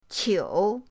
qiu3.mp3